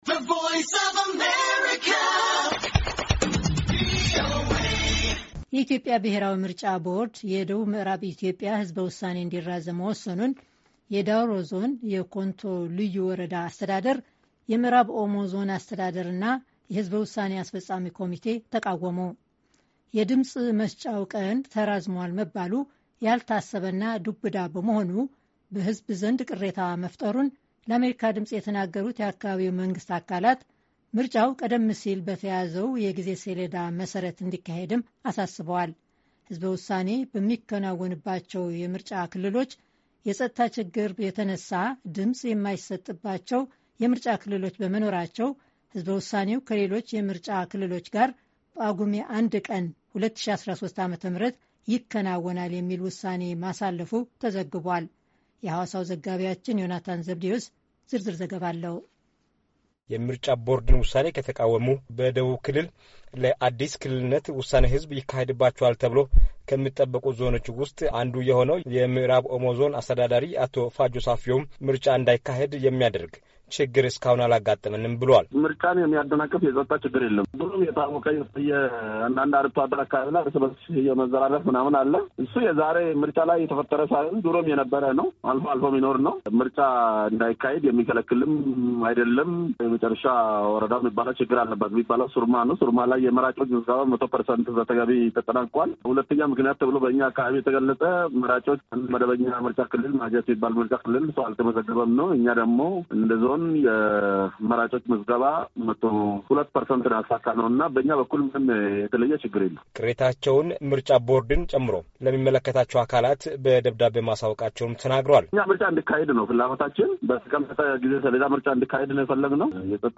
የደቡብ ምዕራብ ክልል ምሥረታ ውሳኔ ህዝብ ምልልሶች